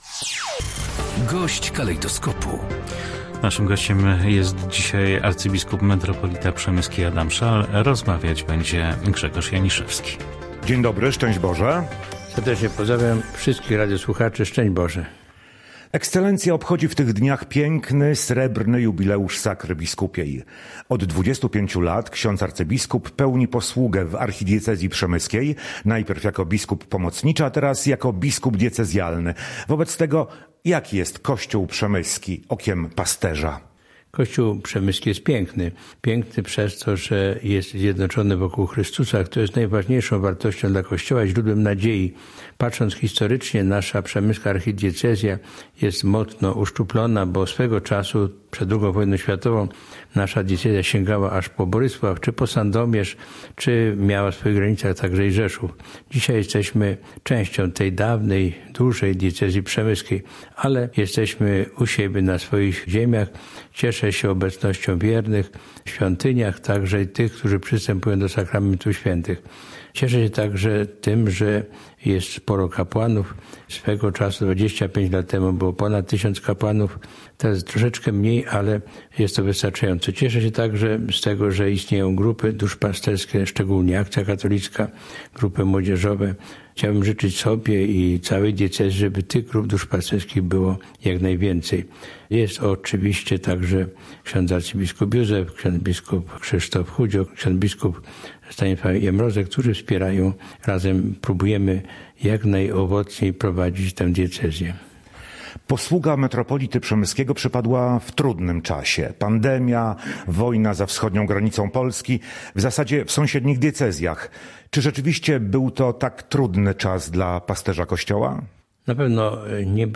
W Wigilię Bożego Narodzenia metropolita przemyski arcybiskup Adam Szal mówił na antenie Radia Rzeszów o aktualnym znaczeniu świąt.
W wigilijnej rozmowie na antenie Polskiego Radia Rzeszów arcybiskup zwracał uwagę, że święta są czasem, który powinien skłaniać do zatrzymania się i refleksji nad tym, co w życiu najważniejsze.